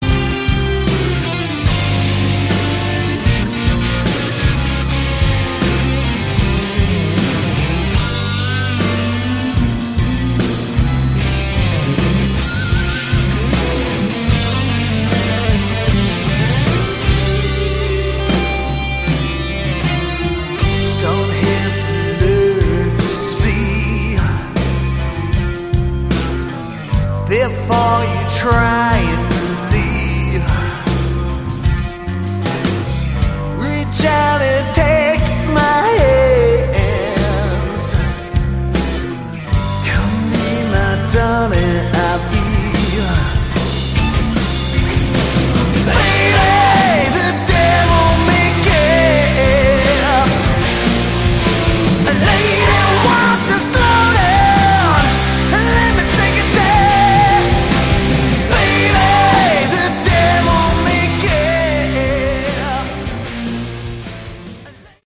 Category: Hard Rock
vocals, guitars
bass, backing vocals
drums, percussion